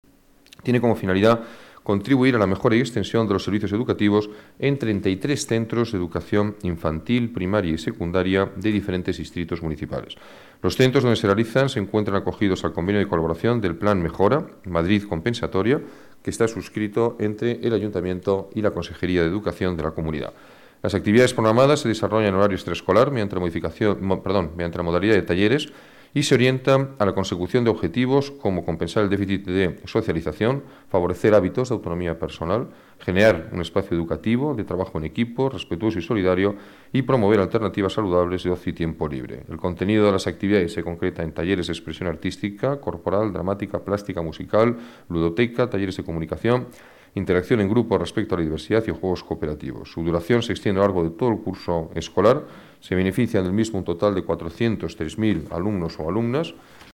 Nueva ventana:Declaraciones del alcalde de Madrid, Alberto Ruiz-Gallardón: actividades extraescoalres